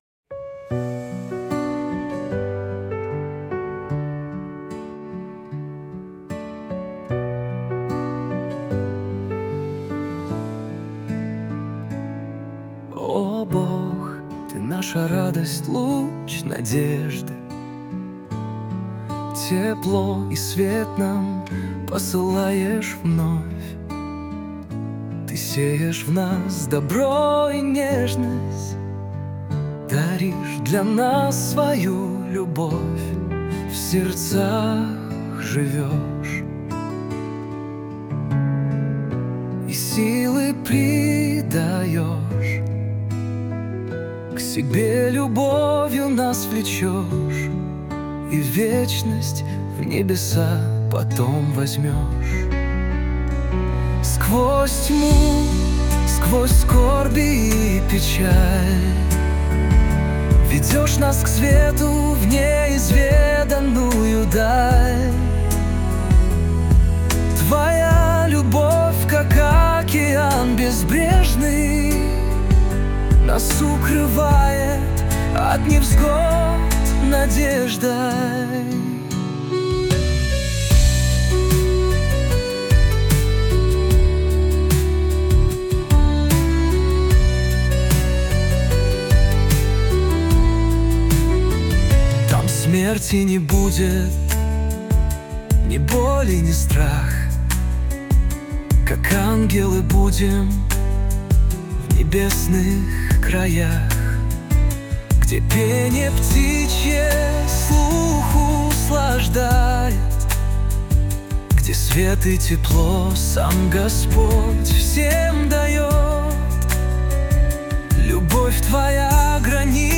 песня ai
333 просмотра 972 прослушивания 78 скачиваний BPM: 76